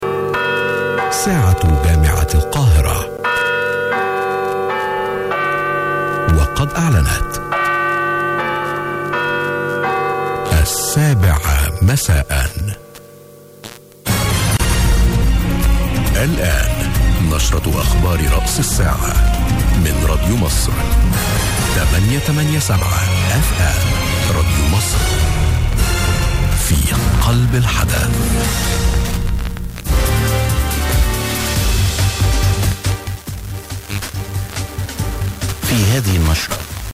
Ionoszférikus terjedések (ES)
"beharangozott" Kairó is...